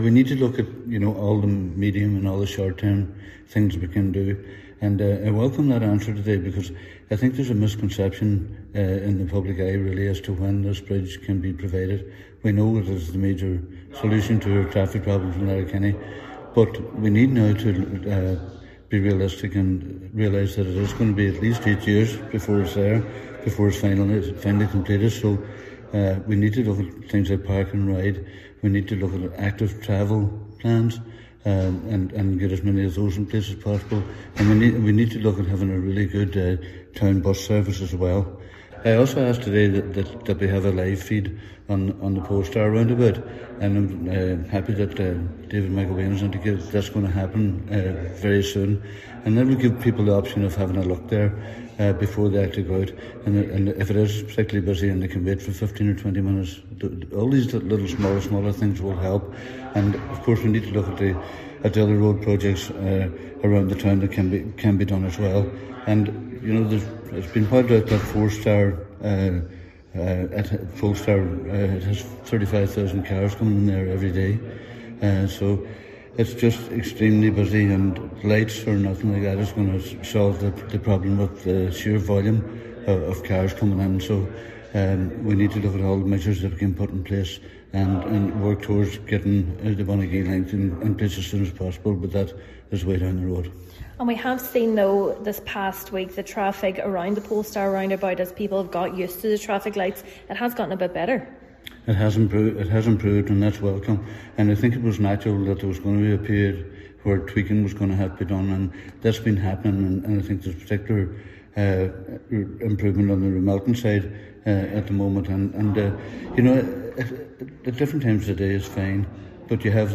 With 35,000 motorists travelling on the Four Lane Route daily, Councillor Jimmy Kavanagh believes alternative travel facilities need to be introduced in the interim: